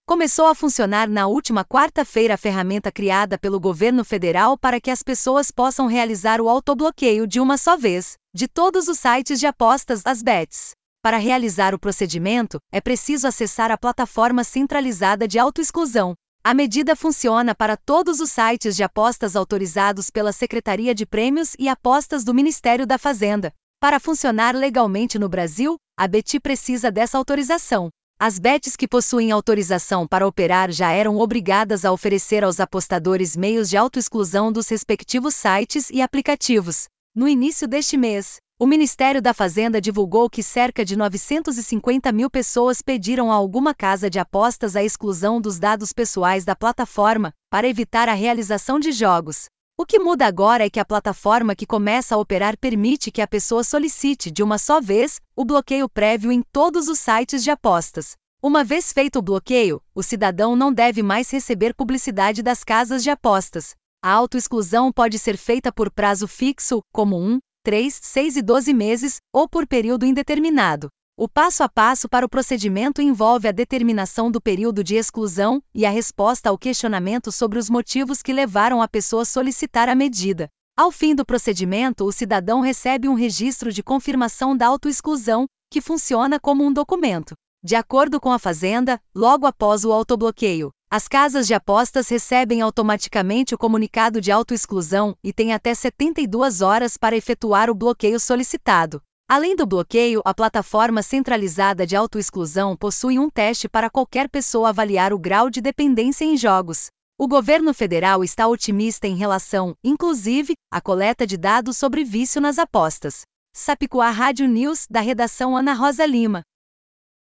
Boletins de MT 12 dez, 2025